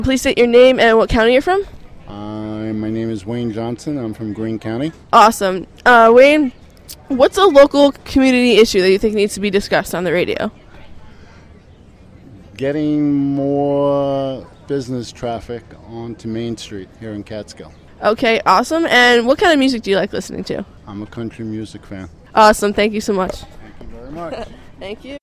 Interview
Greene County Youth Fair: Jul 22, 2010 - Jul 25, 2010